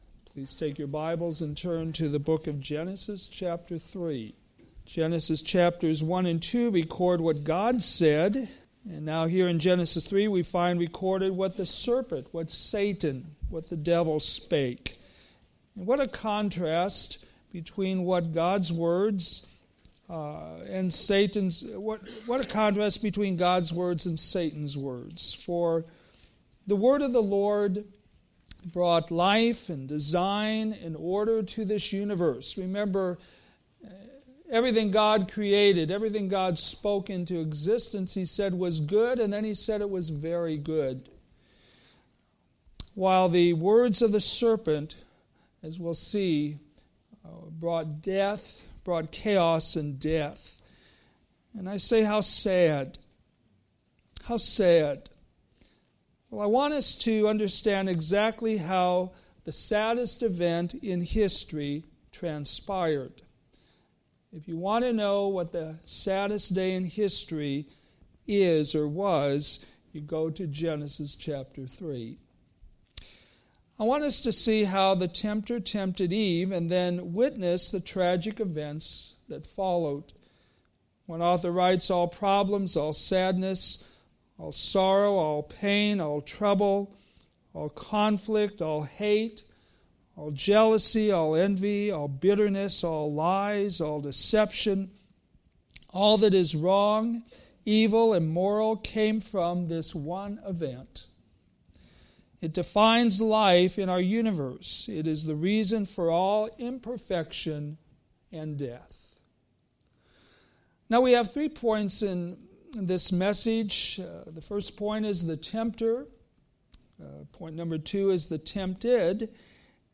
Sermons based on Old Testament Scriptures